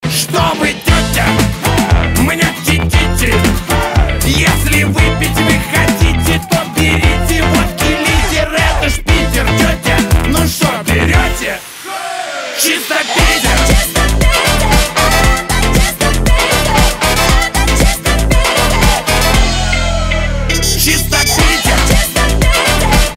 • Качество: 320, Stereo
веселые
смешные
блатные